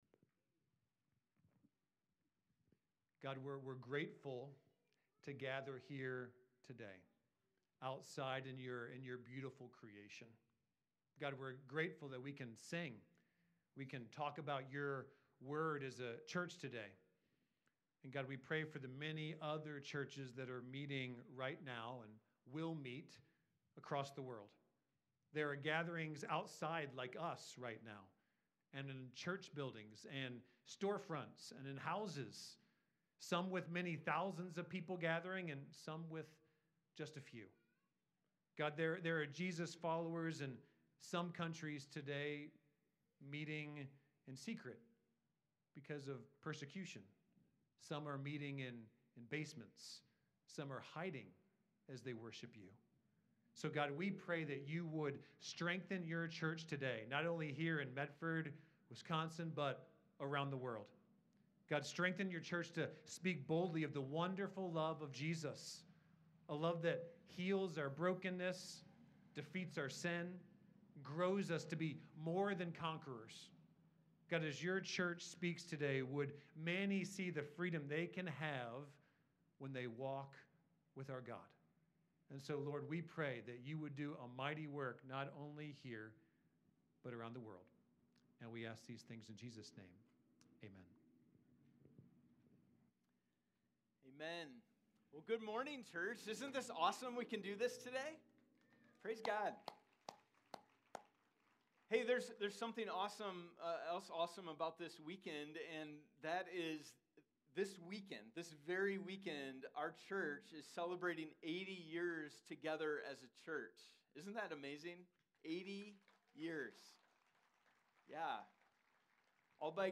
Worship in the Park Service | “Centerpiece” | 1 Corinthians 2:1-5